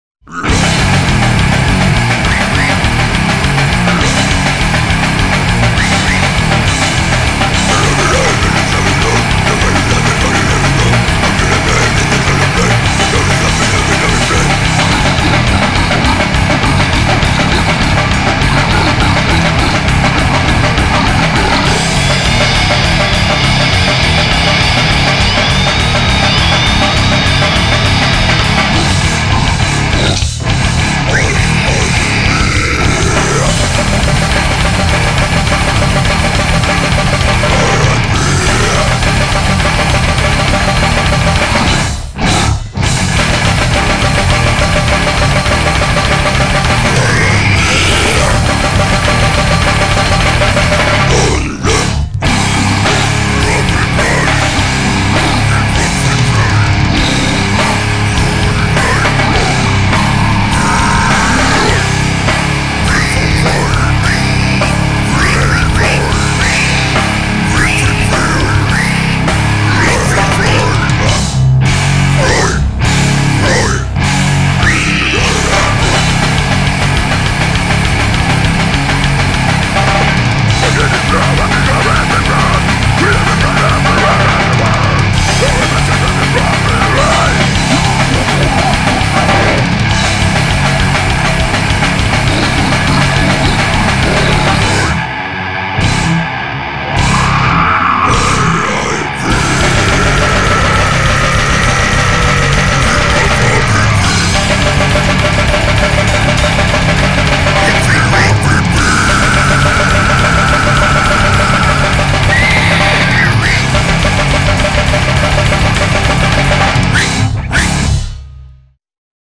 boost bass
guitar
drums
vocals